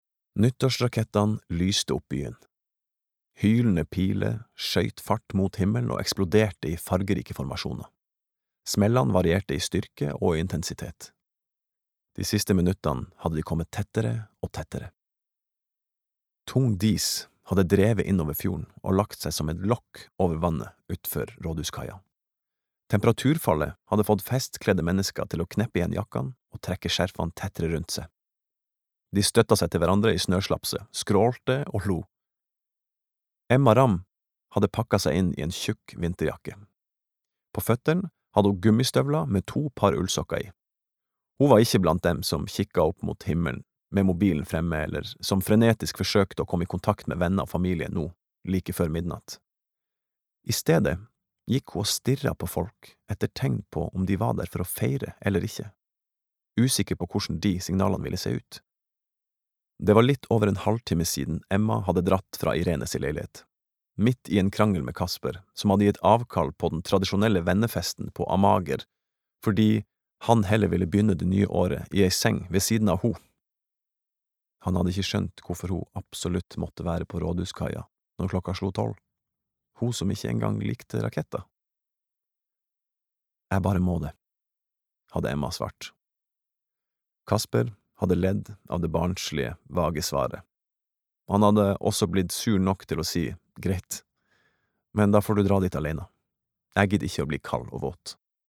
Nordnorsk fra Tromsø